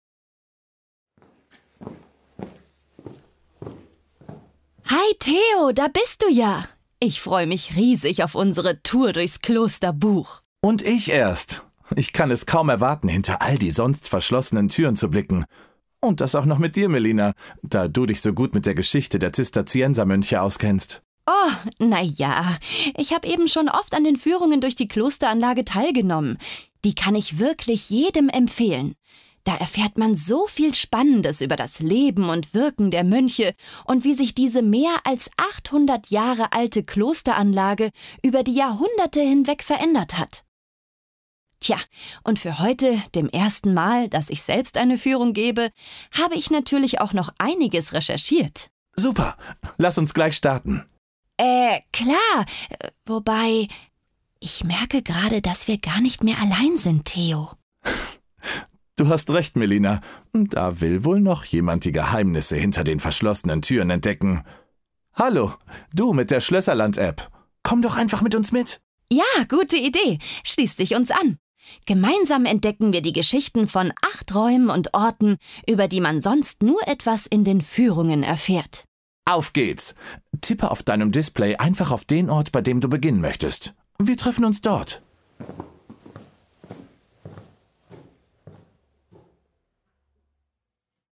Gehe mit Melina und Theo auf eine Tour durch das Kloster Buch und entdecke so die Räume. Mit dem Audioguide in unserer App kannst du so vor Ort und auch zuhause spannendes lernen!
D 001 Begrüßung_Buch.mp3